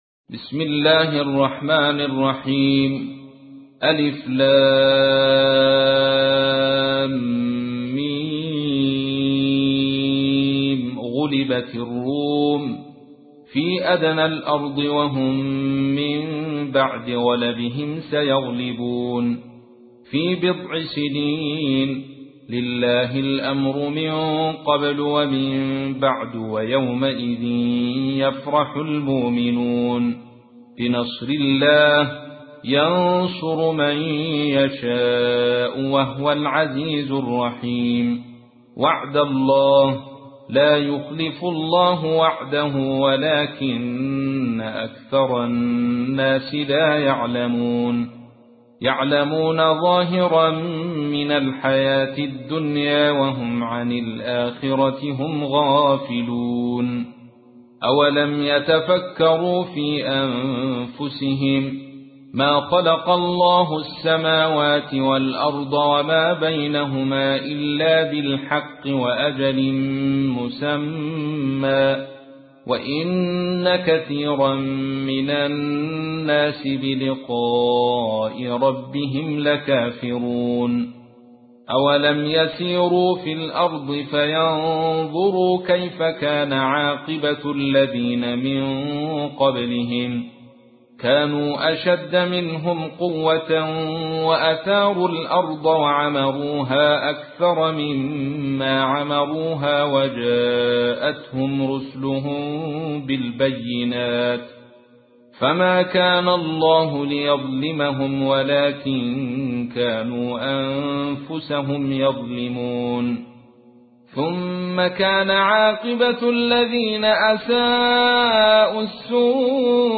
تحميل : 30. سورة الروم / القارئ عبد الرشيد صوفي / القرآن الكريم / موقع يا حسين